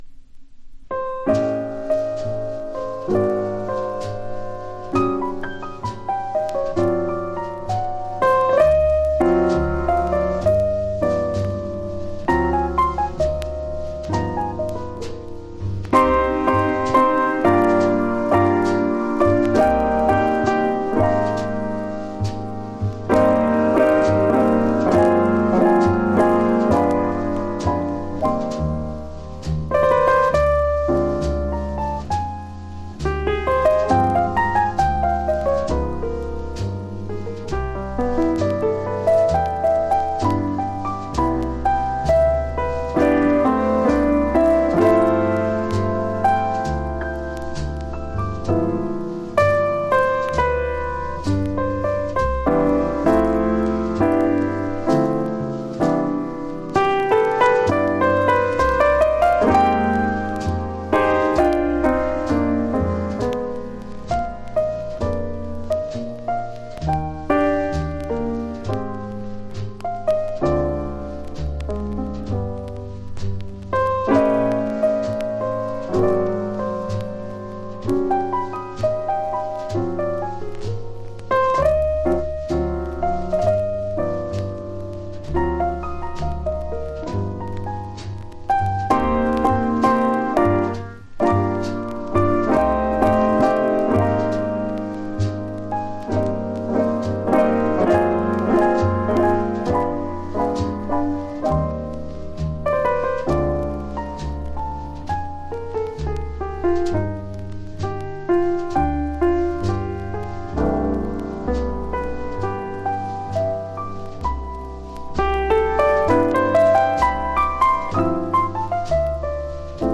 9曲トリオ、4曲トリオ+コンガ